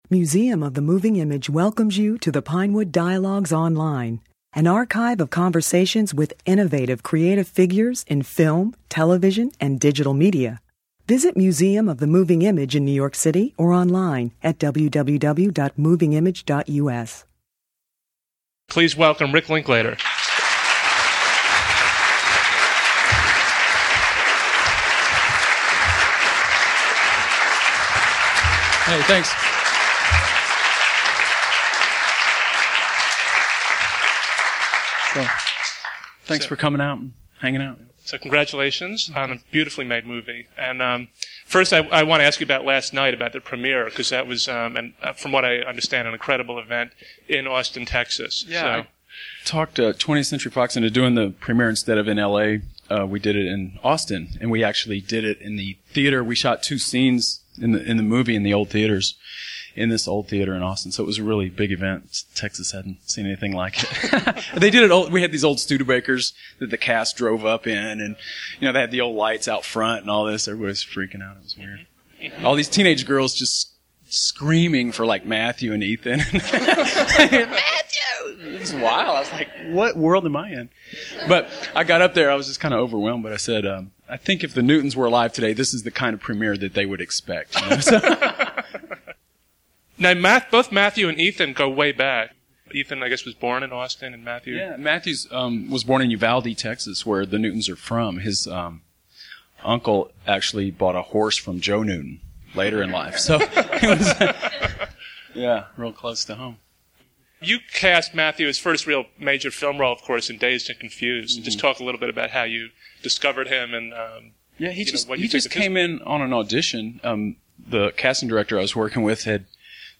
At a preview screening of the film at the Museum of the Moving Image, Linklater talks about his discovery of this film's would-be legendary characters, and how he evokes naturalistic performances from his actors.